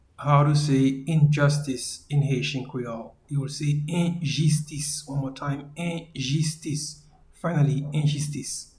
Injustice-in-Haitian-Creole-Enjistis.mp3